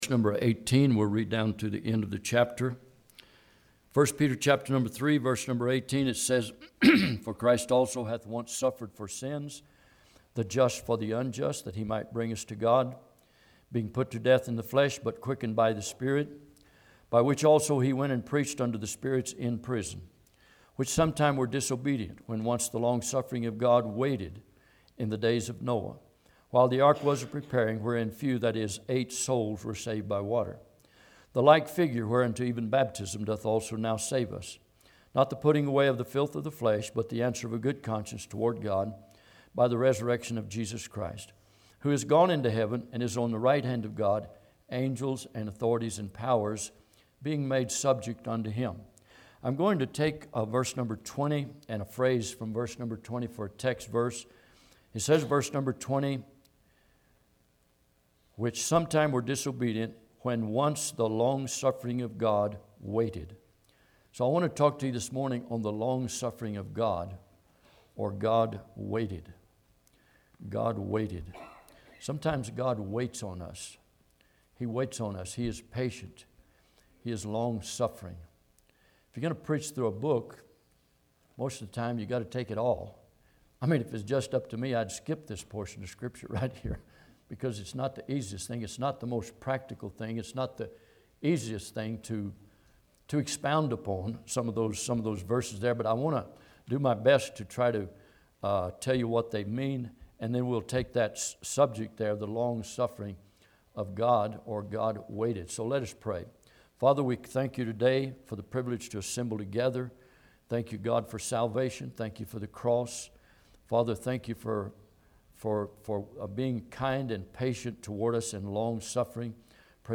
I Peter 3:18-22 Service Type: Sunday am Bible Text